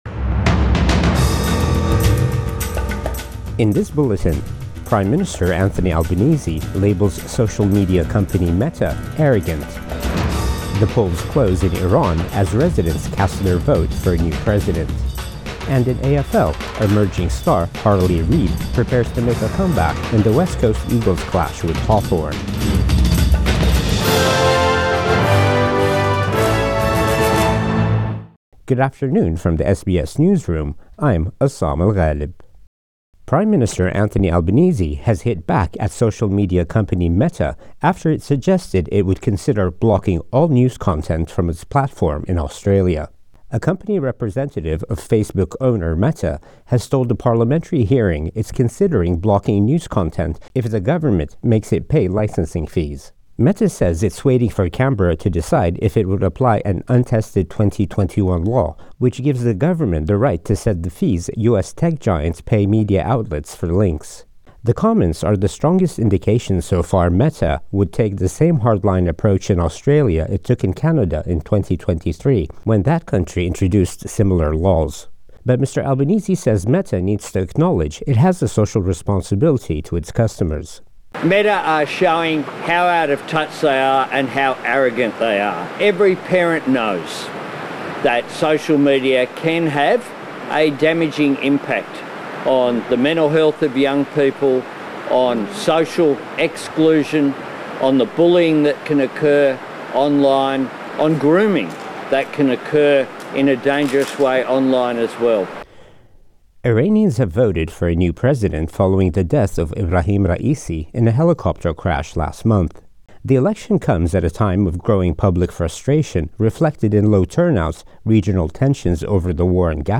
Midday News Bulletin 29 June 2024